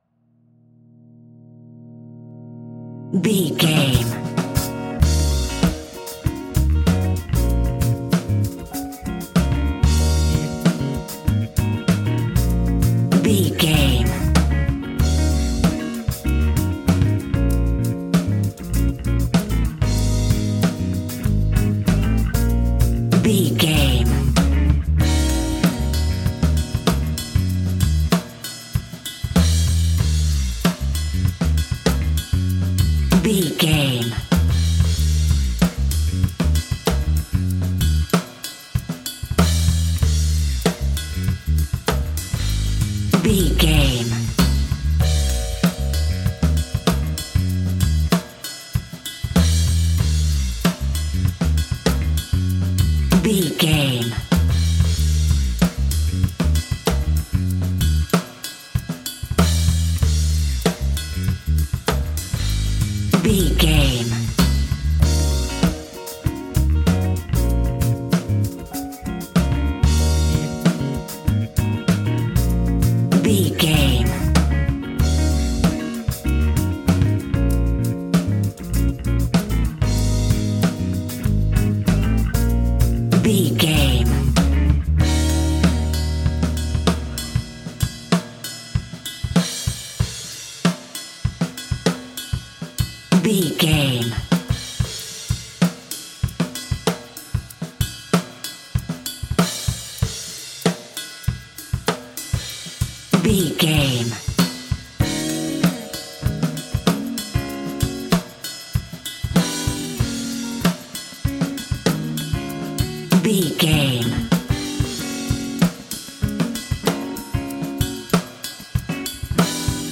Fast paced
Uplifting
Ionian/Major
A♯
instrumentals